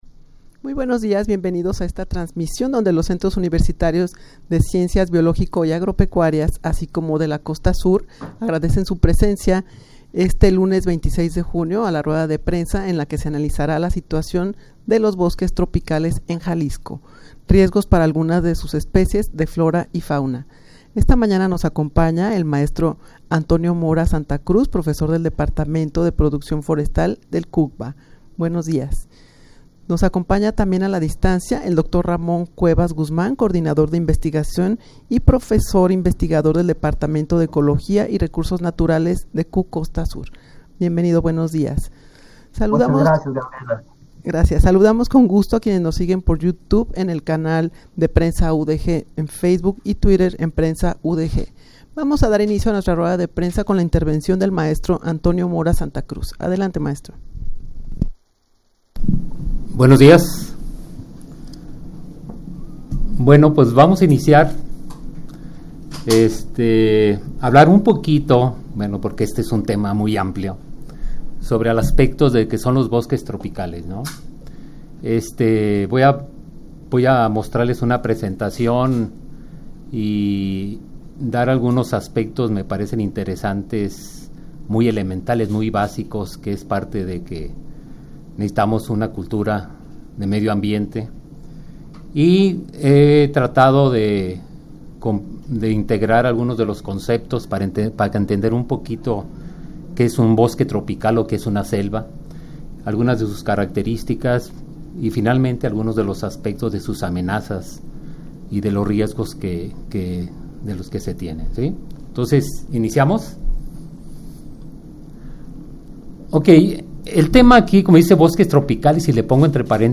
Audio de la Rueda de Prensa
rueda-de-prensa-en-la-que-se-analizara-la-situacion-de-los-bosques-tropicales-en-jalisco.mp3